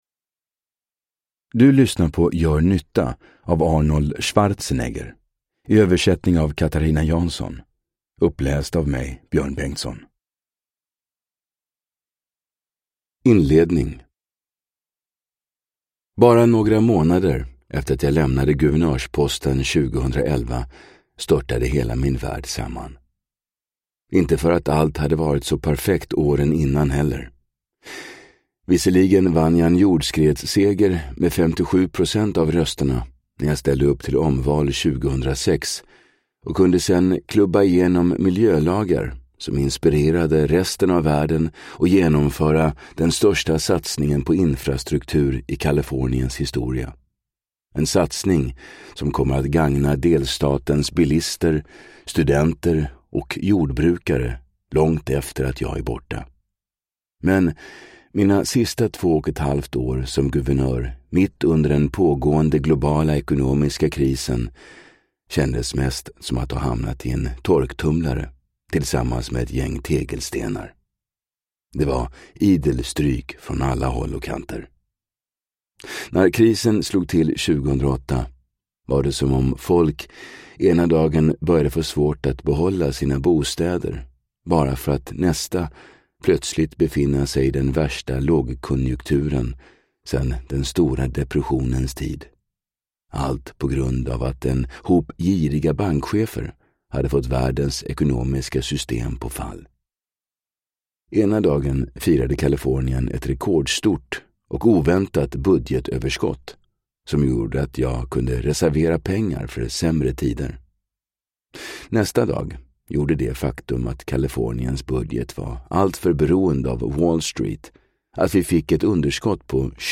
Gör nytta : sju verktyg för livet – Ljudbok – Laddas ner